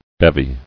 [bev·y]